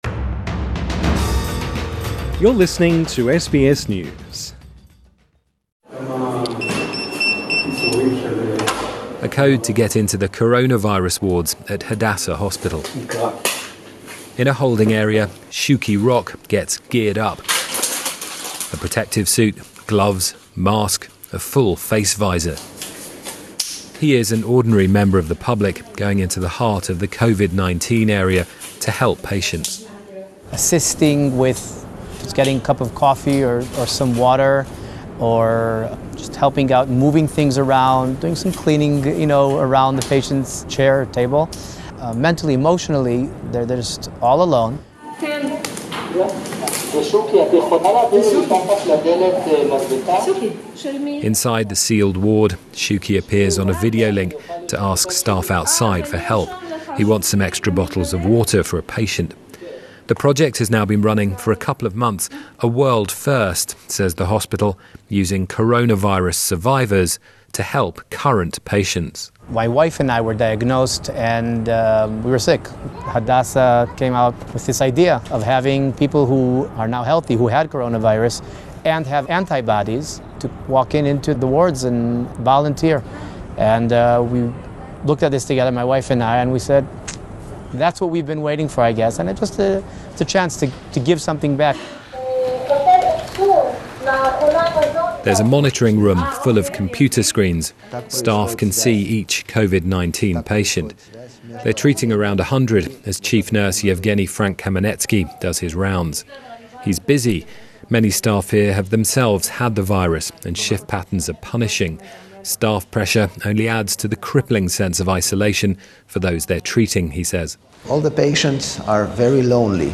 This report was first produced for the BBC World Service